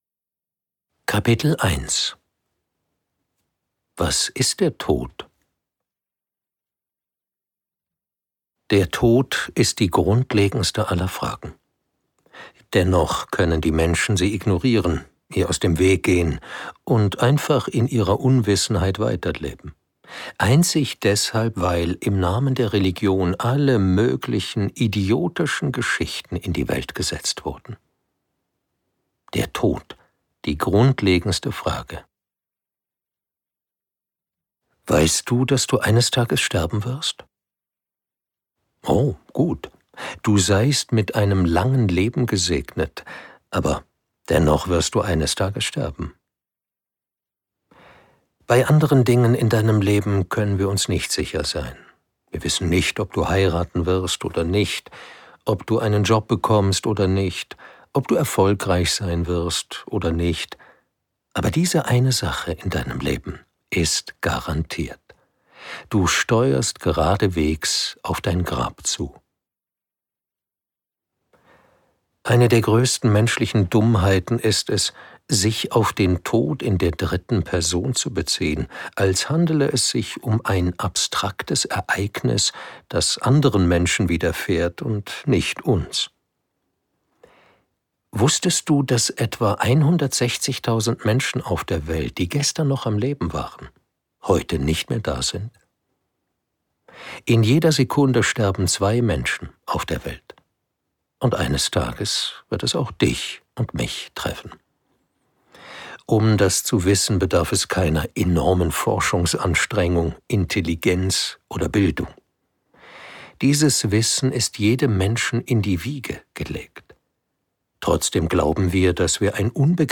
Leseprobe